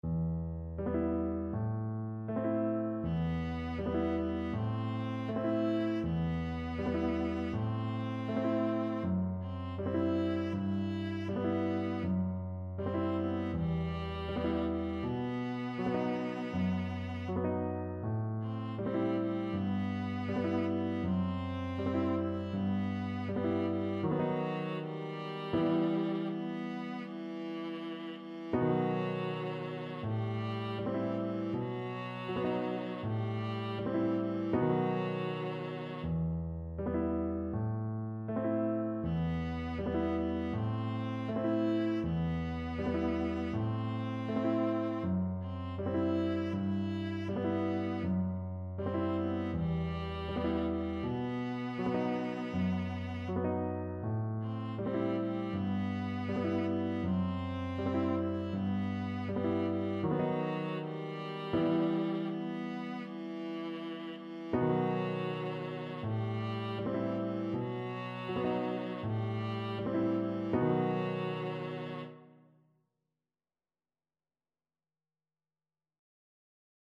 Viola
Traditional Music of unknown author.
Andante
E minor (Sounding Pitch) (View more E minor Music for Viola )
E4-E5